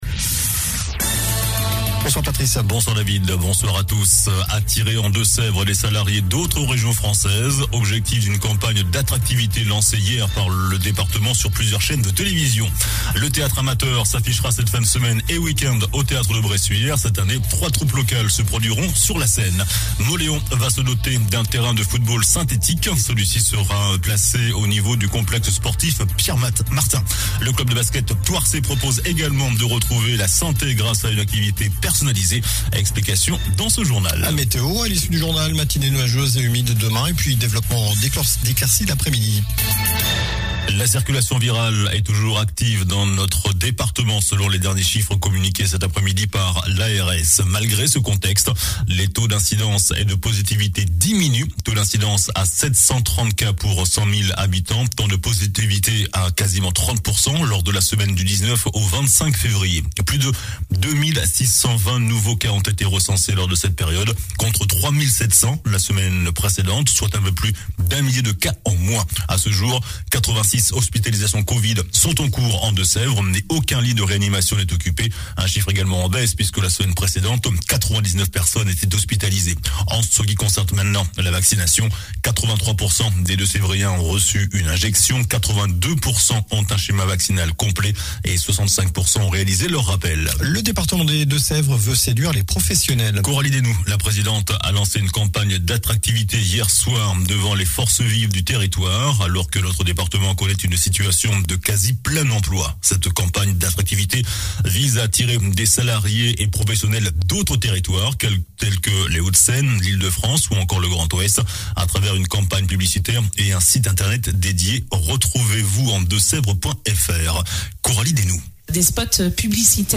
JOURNAL DU MARDI 01 MARS ( SOIR )